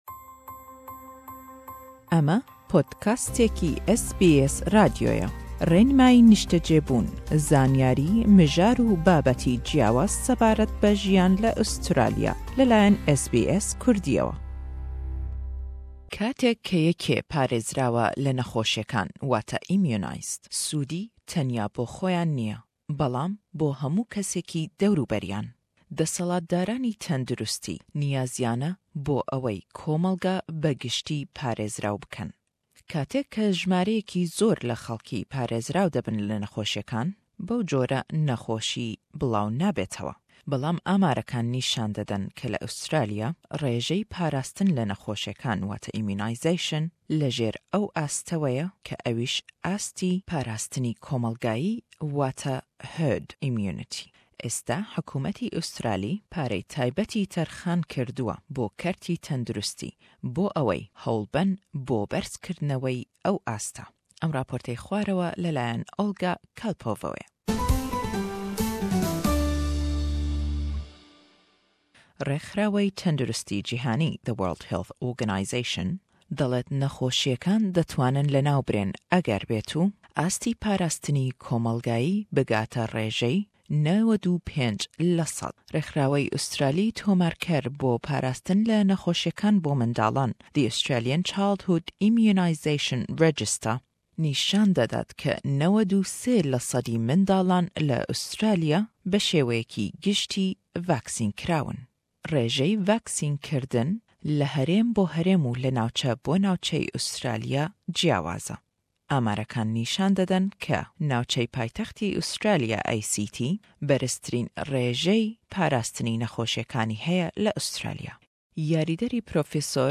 Amanca dezgehên tendirûstiyê xwe bigînin nav civakê jibo her kes derziyên kutanê li zarokên xwe xin. Raport bi Kurdî/Îngilîzî.